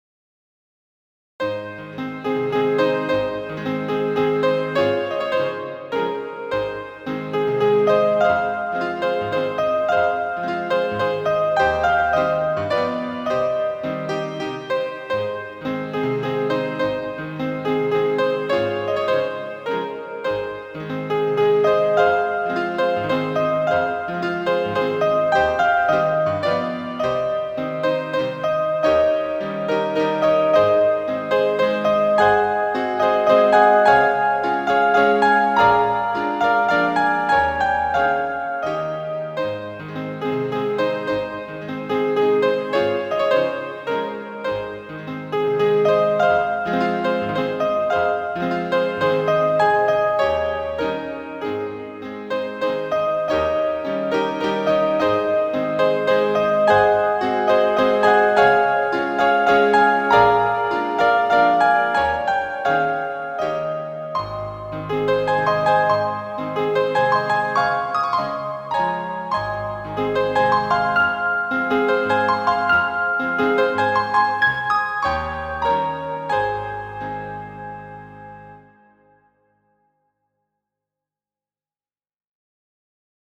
Lulkanto, verko 39, numero 15-a de Johano Brahms.
lulkanto.mp3